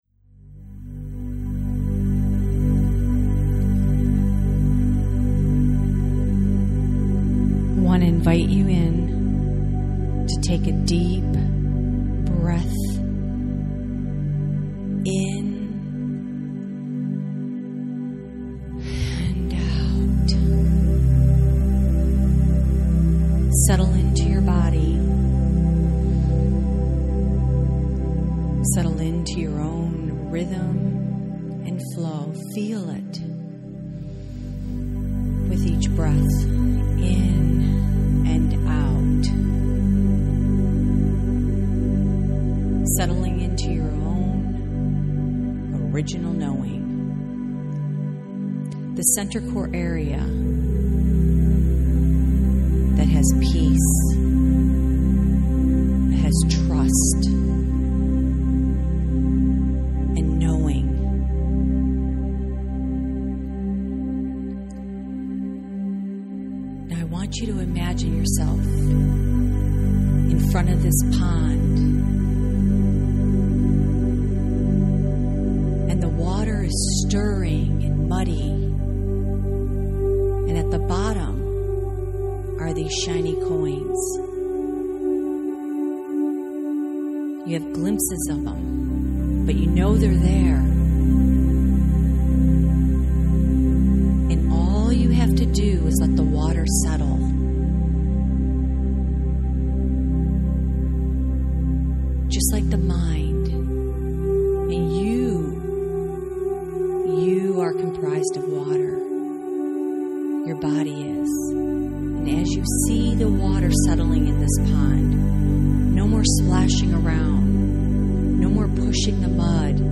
Miracle-Meditation-Manifesting.mp3